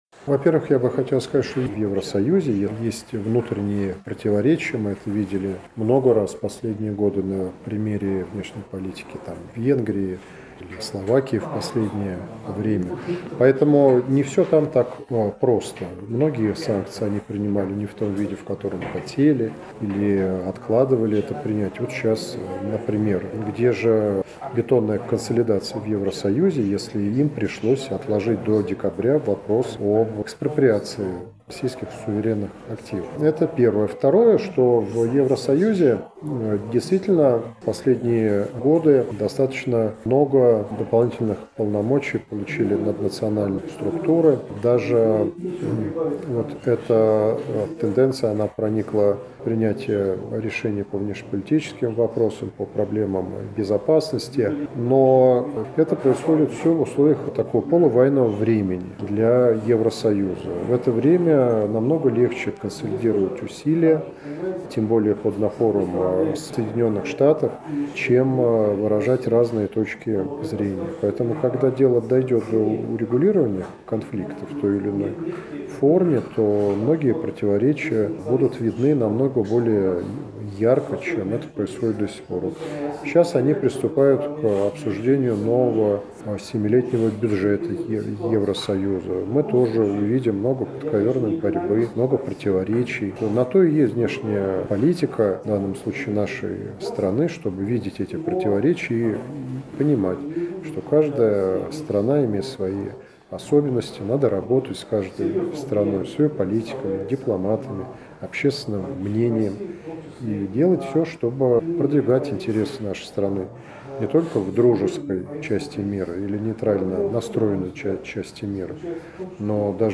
Доктор политических наук, член-корреспондент РАН, директор Института Европы РАН Алексей Громыко в интервью журналу «Международная жизнь» рассказал о текущей ситуации в Европейском Союзе: